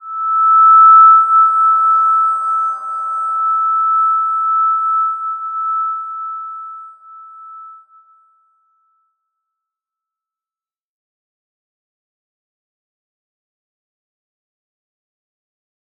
Slow-Distant-Chime-E6-mf.wav